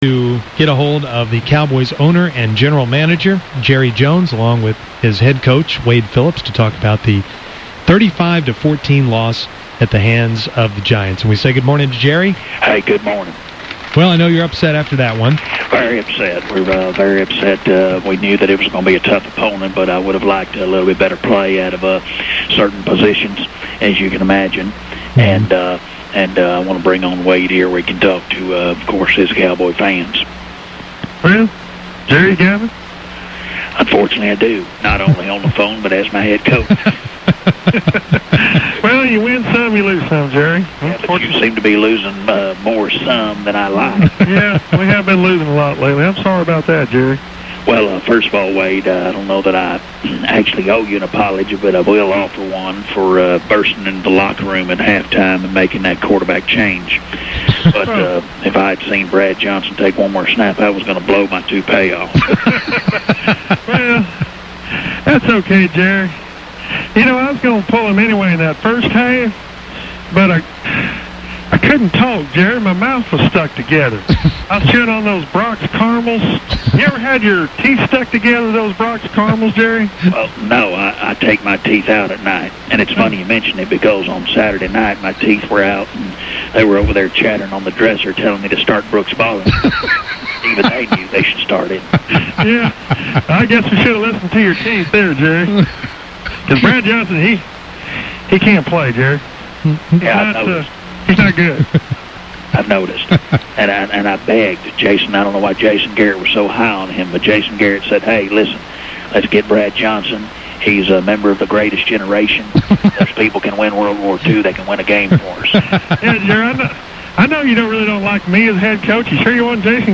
Fake Jerry is getting fed up. Fake Wade is still positive and country as ever.